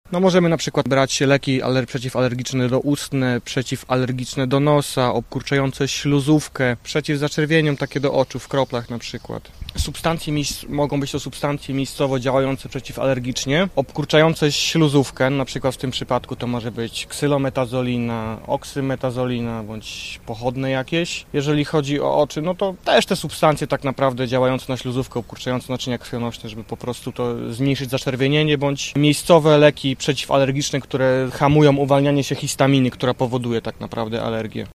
Co znajdziemy w ich składach? – wyjaśnia farmaceuta